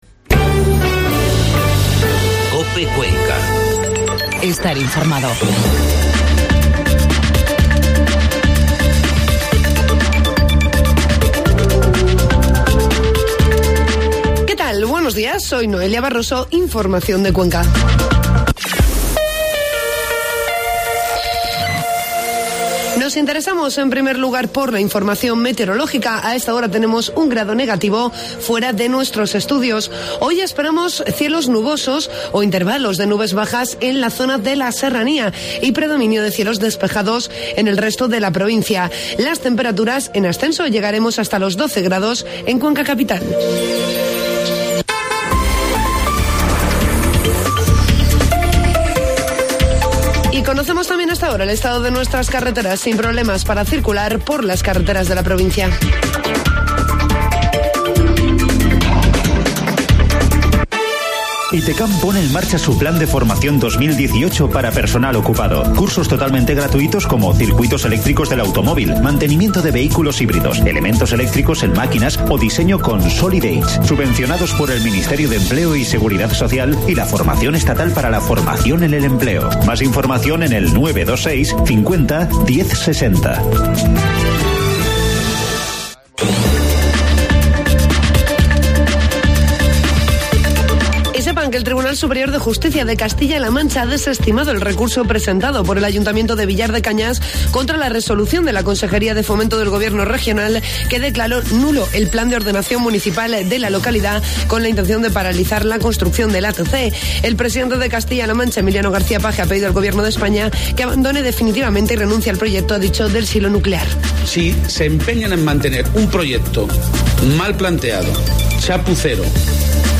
Informativo matinal COPE Cuenca 16 de enero
AUDIO: Informativo matinal